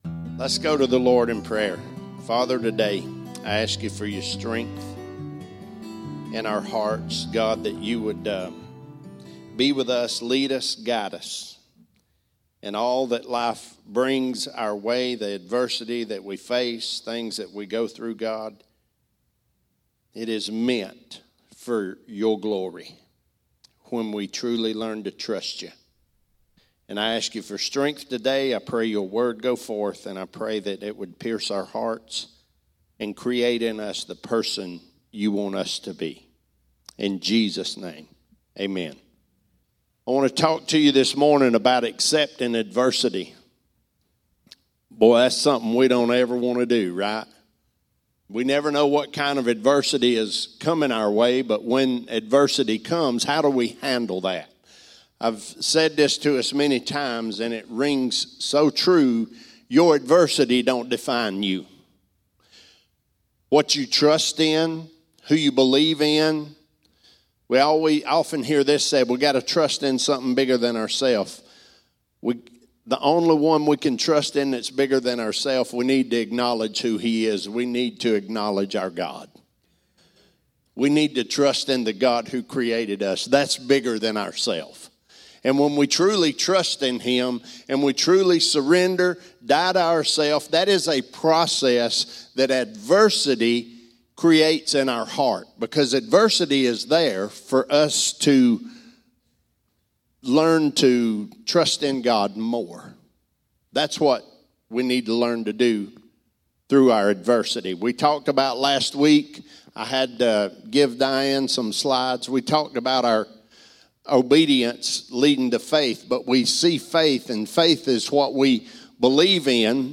Sermons | Living for the Brand Cowboy Church of Athens